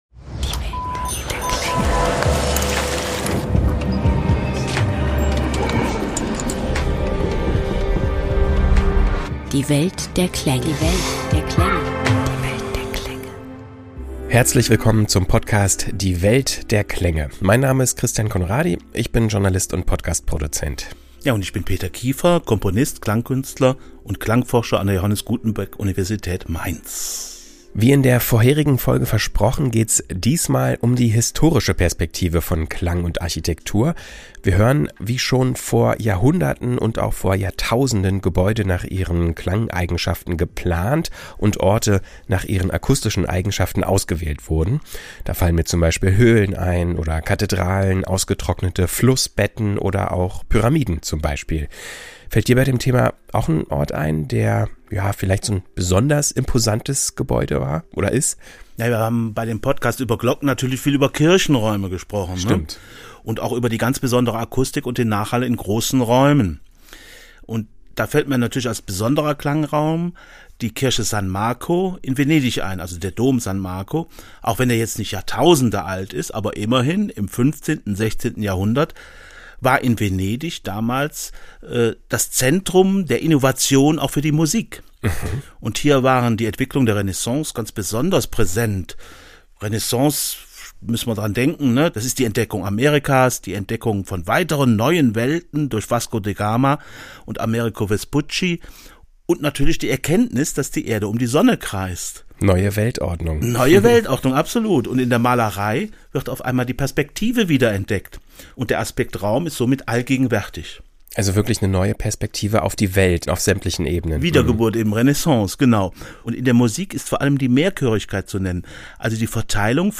Zum Schluss dürft ihr Mitraten: Könnt ihr anhand des simulierten Nachhalls erraten, ob ihr gerade in einer Blumenvase, der Berliner Philharmonie, im Gasometer Oberhausen oder sogar in der Königskammer der Pyramide von Gizeh steht?